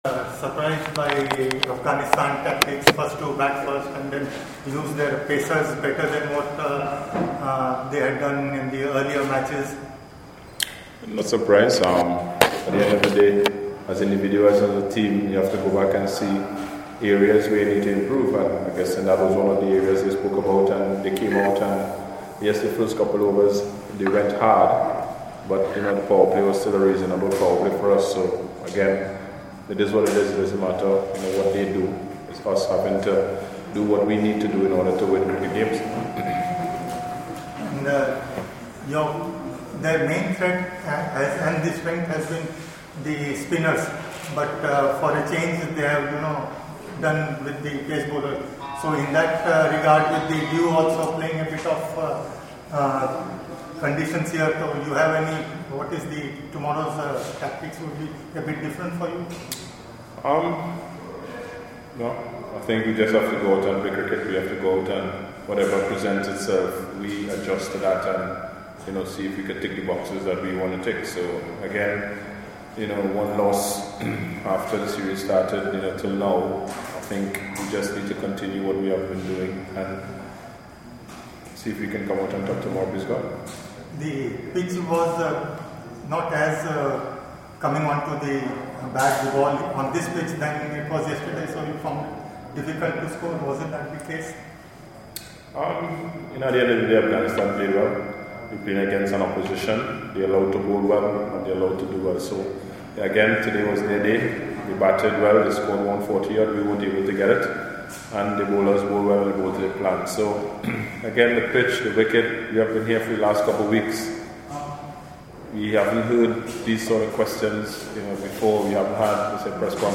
West Indies captain Kieron Pollard spoke to members of the media after the second T20 International against Afghanistan at the Ekana Cricket Stadium. West Indies lost the match by 41 runs as the hosts levelled the series 1-1.